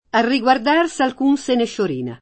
sciorinare v.; sciorino [+šor&no] — es.: A riguardar s’alcun se ne sciorina [